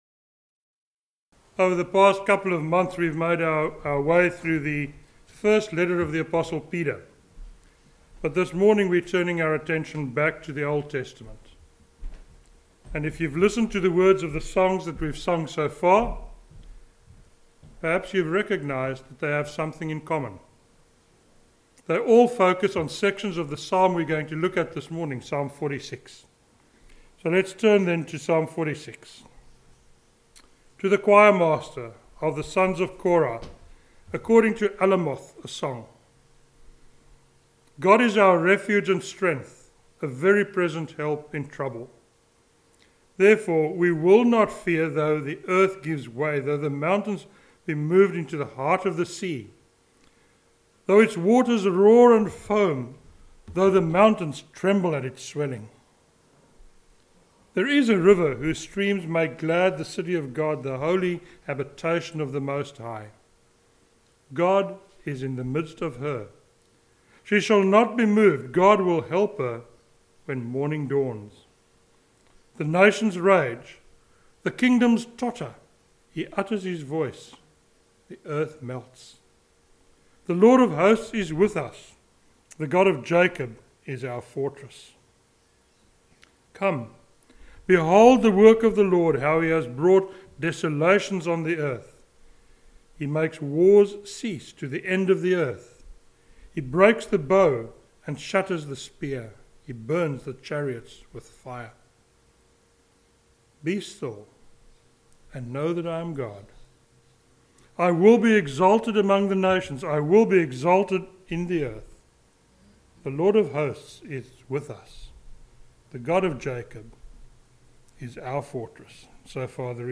a sermon on Psalm 46